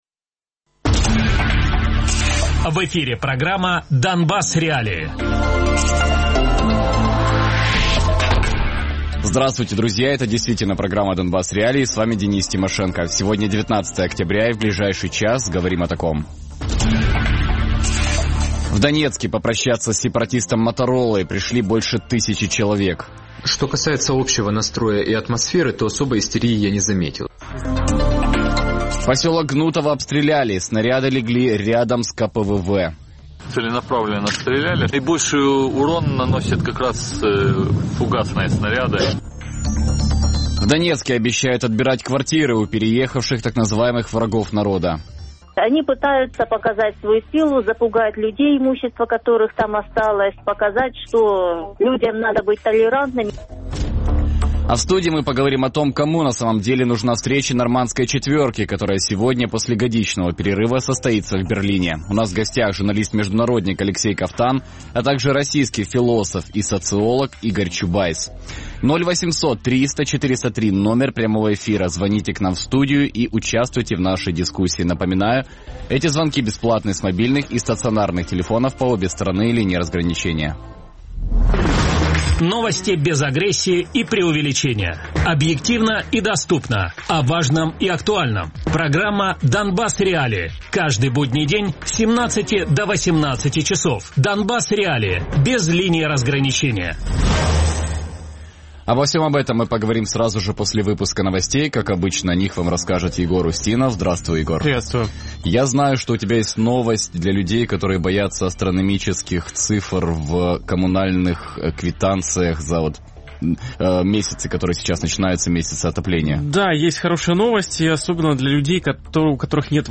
российский философ и социолог Радіопрограма «Донбас.Реалії» - у будні з 17:00 до 18:00.